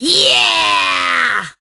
penny_lead_vo_03.ogg